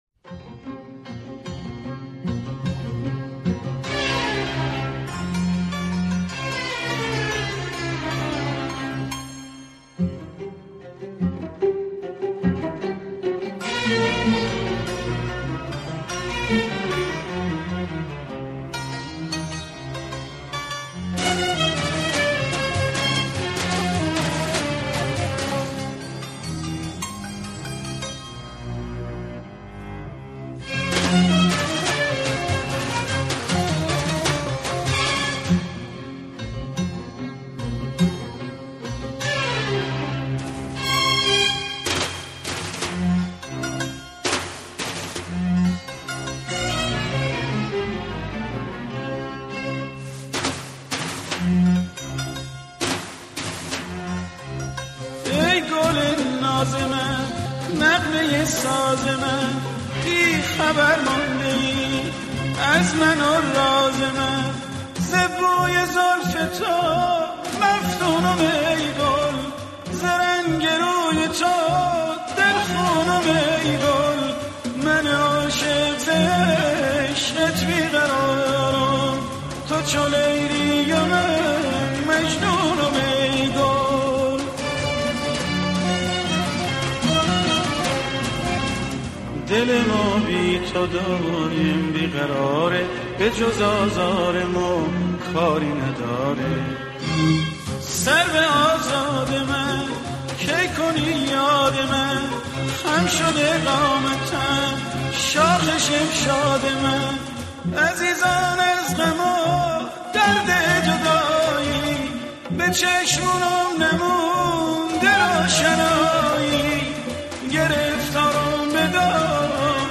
موسیقی سنتی